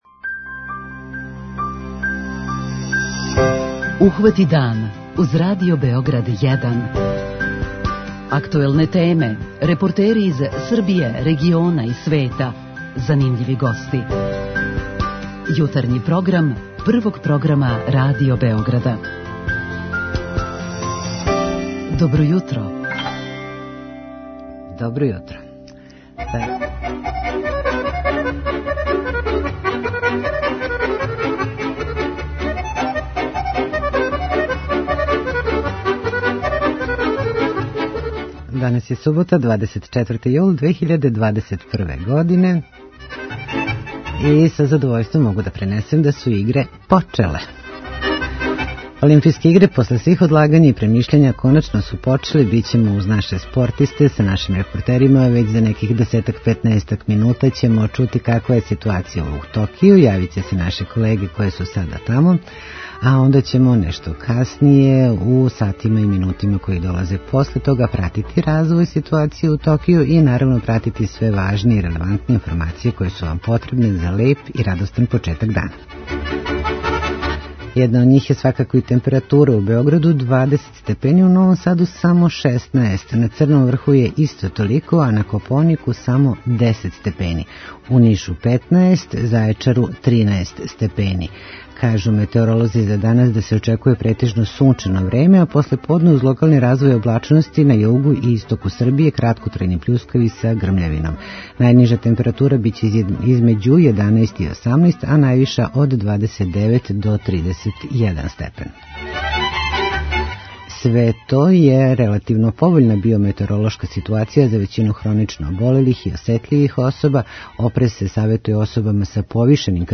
Олимпијске игре, после свих одлагања и премишљања, су коначно почеле! Бићемо уз наше спортисте на свим такмичењима која им предстоје, а наши репортери ће и у данашњој емисији пренети делиће атмосфере из Токија.
преузми : 26.99 MB Ухвати дан Autor: Група аутора Јутарњи програм Радио Београда 1!